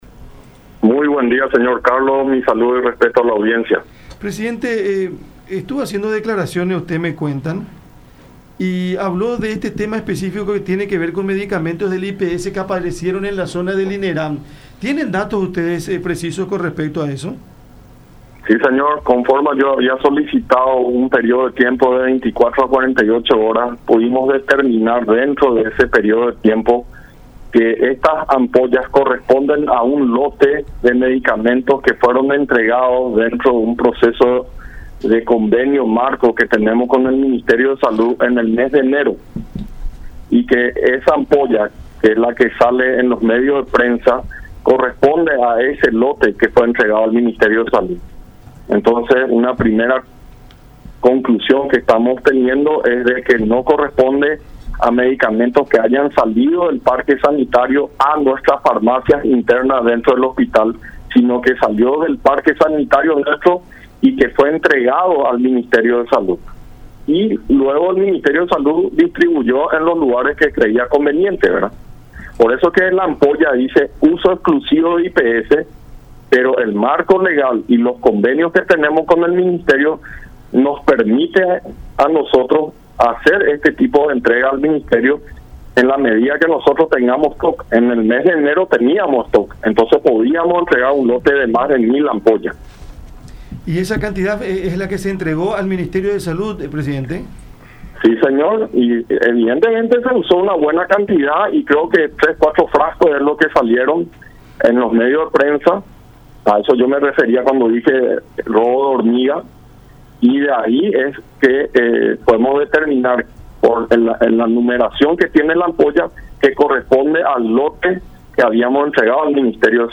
Podemos comprobar por la numeración los remedios”, expuso Gubetich en conversación con La Unión.